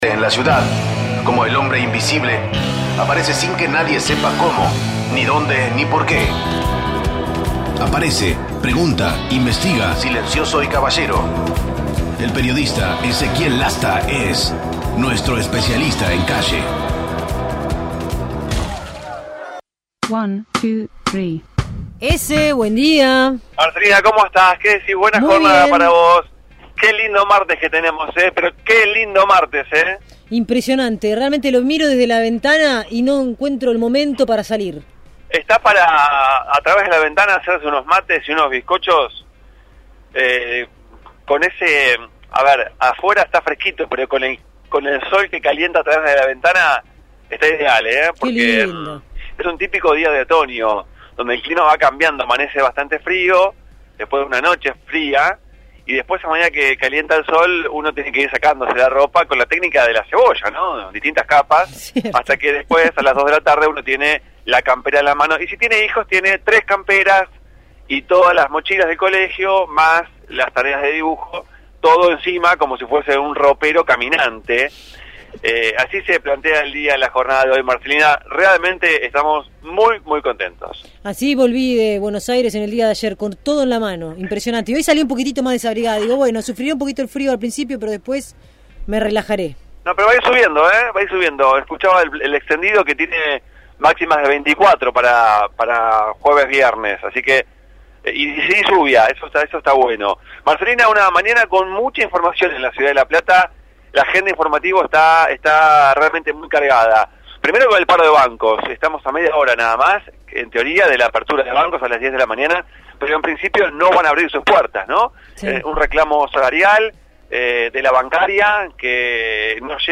MÓVIL/ Problemas edilicios en el Juzgado de Familia